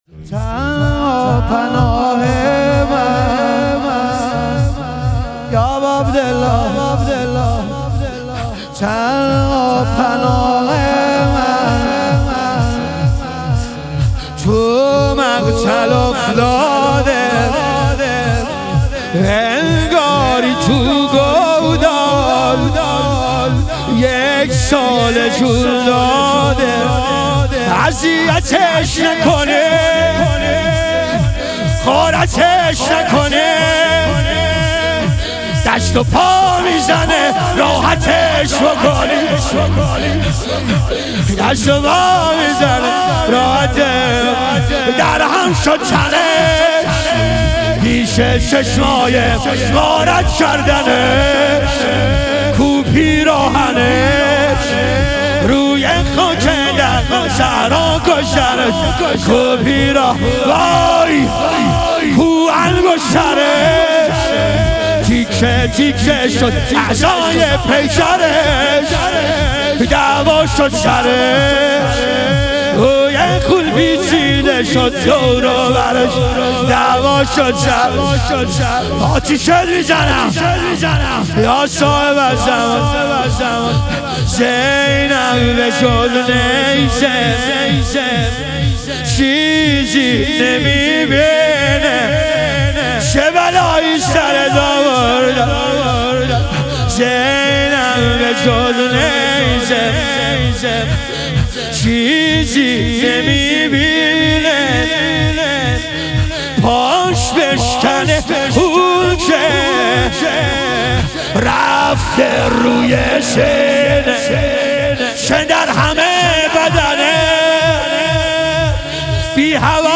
شام غریبان حضرت زهرا علیها سلام - لطمه زنی